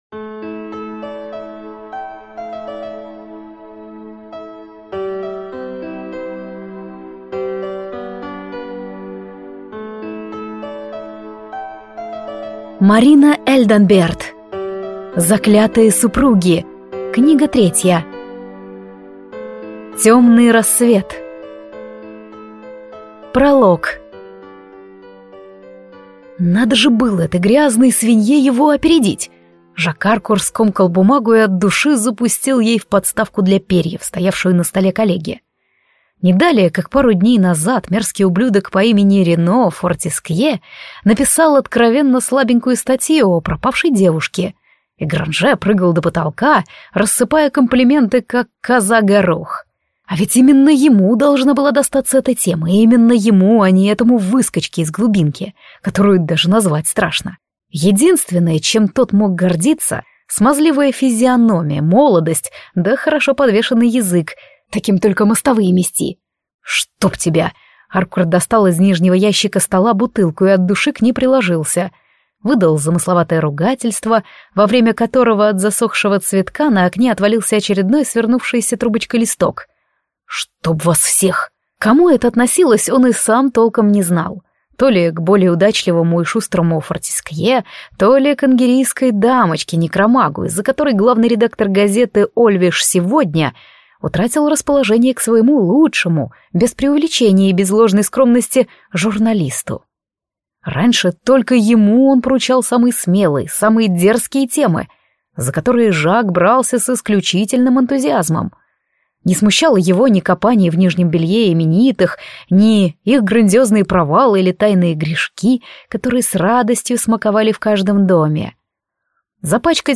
Аудиокнига Заклятые супруги. Темный рассвет | Библиотека аудиокниг